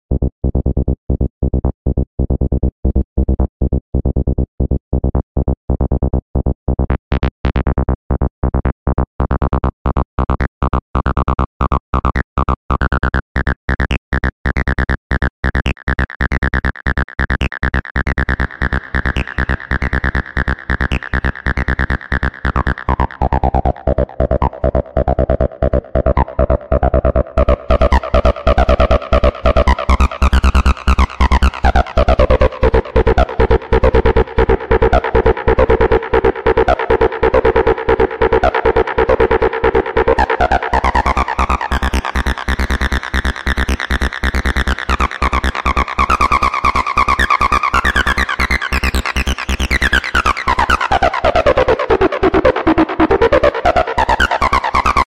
Making Acid Sounds with the sound effects free download